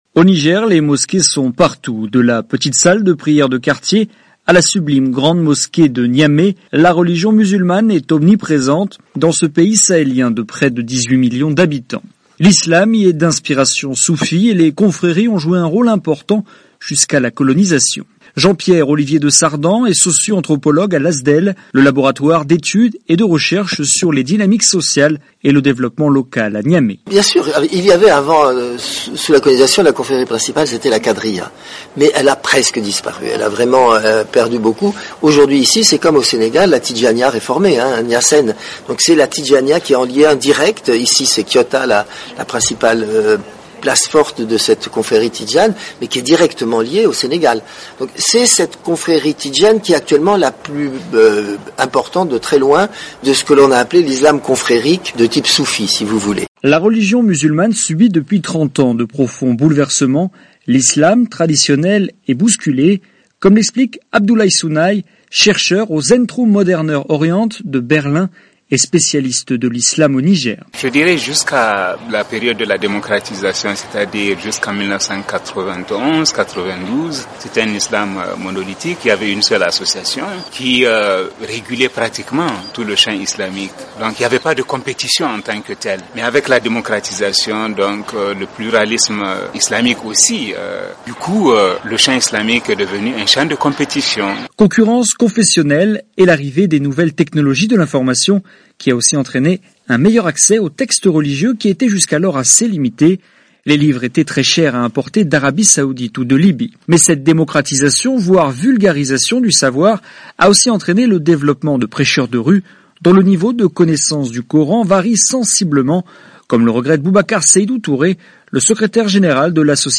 Reportage sur l'Islam au Niger